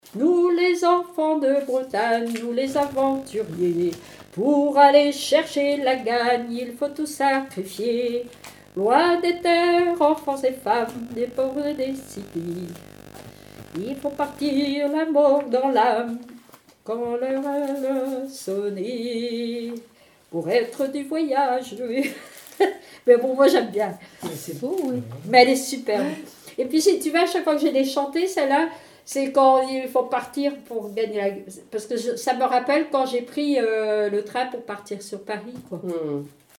Genre strophique
Témoignages sur les chansons
Pièce musicale inédite